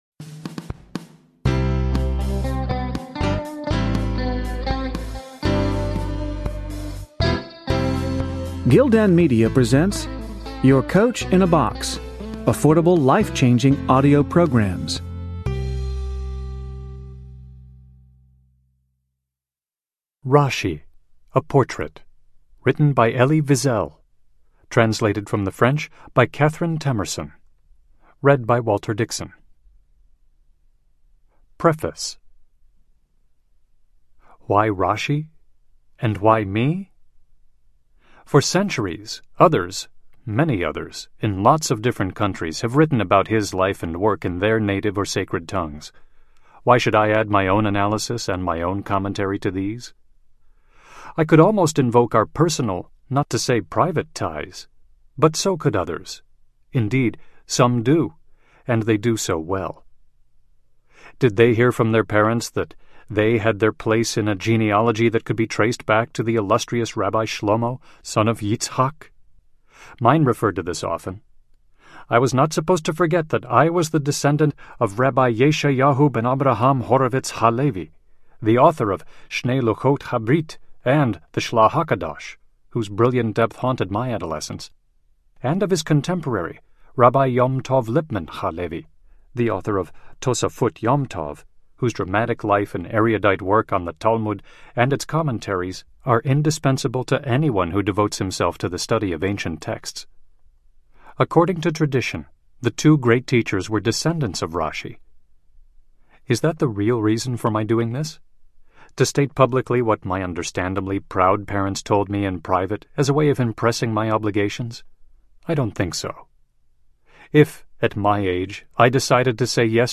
Rashi Audiobook
2.5 Hrs. – Unabridged